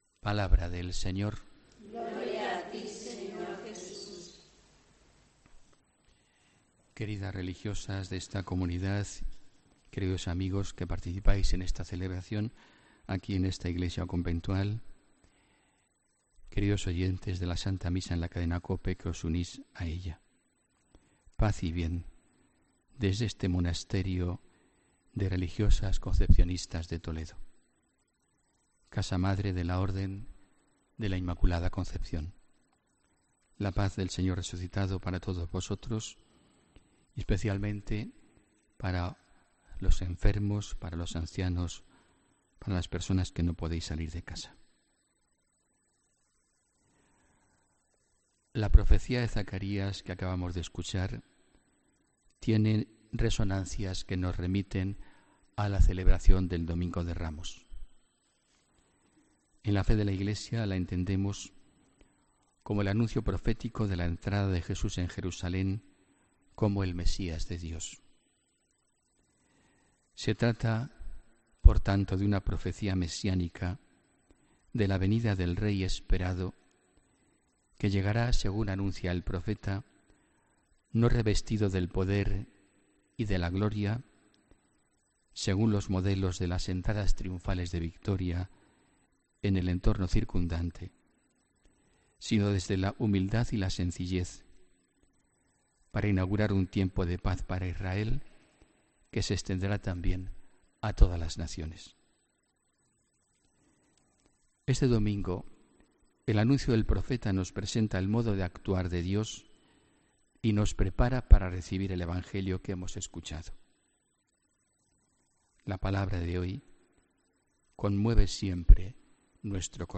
Homilía del domingo 9 de julio de 2017